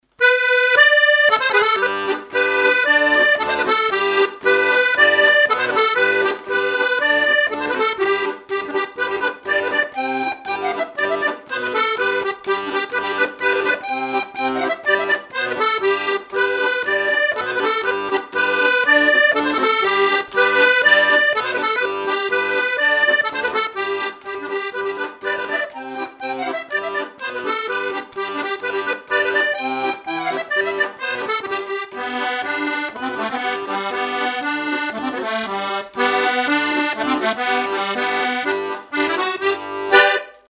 Mémoires et Patrimoines vivants - RaddO est une base de données d'archives iconographiques et sonores.
Polka
Chants brefs - A danser
danse : polka piquée
Pièce musicale inédite